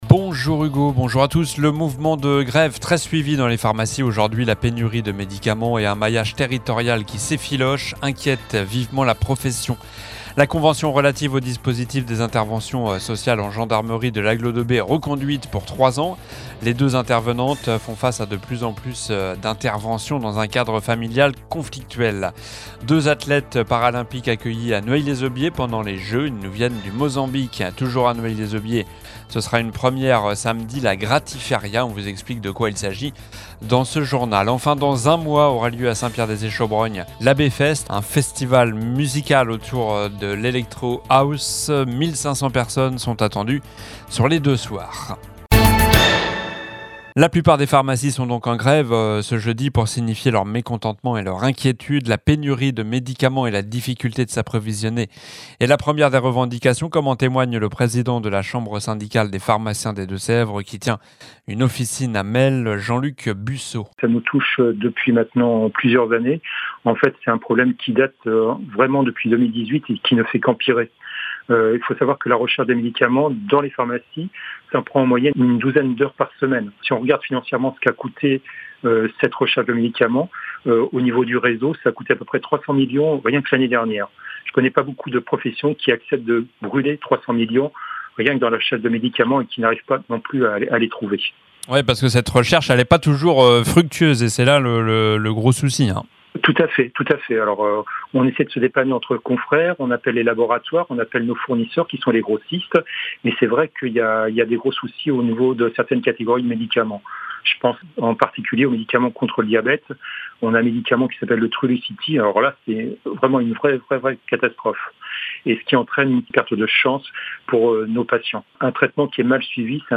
L'info près de chez vous